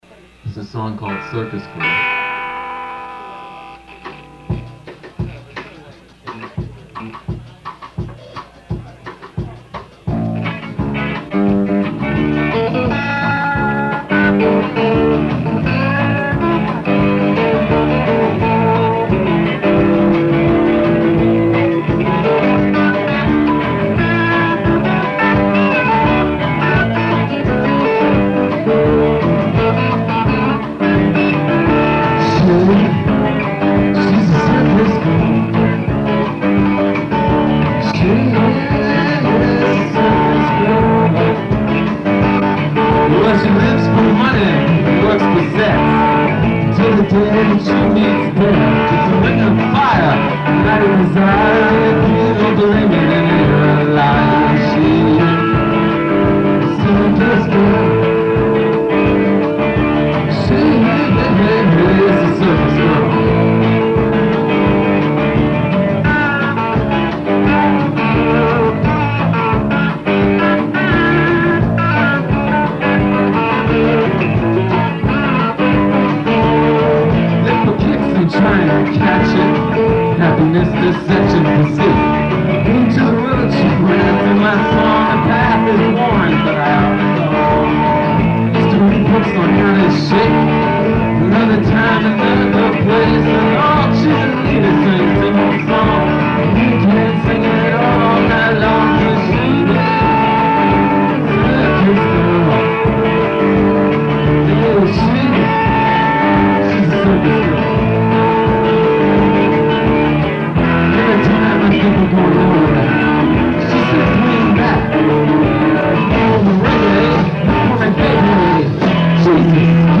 Live Philadelphia